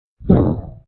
Boss_COG_VO_grunt.mp3